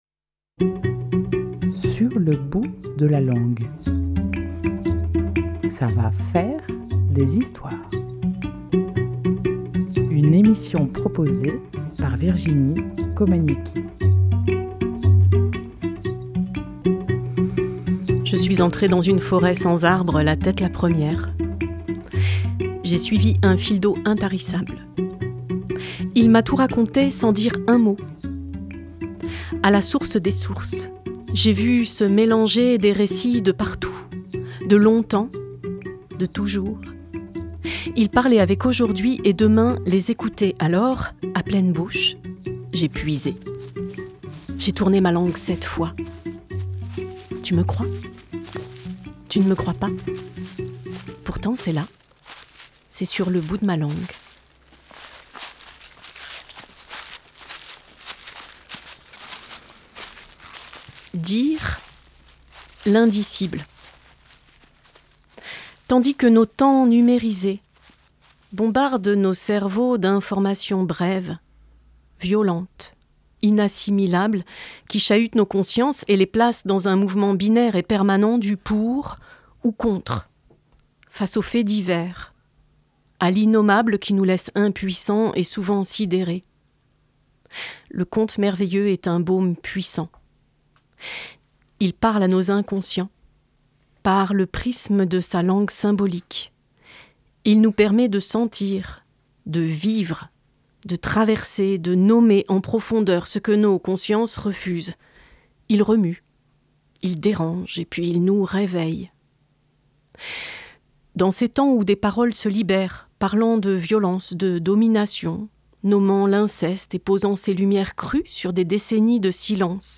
Tandis qu’aujourd’hui des paroles se libèrent, posant une lumière crue sur des décennies de silence, le conte merveilleux nous offre depuis toujours une langue symbolique puissante pour dire et transformer en nous ce qui danse en silence, éclairer la cave et y laisser monter le chant de quelques ossements enfouis. Contes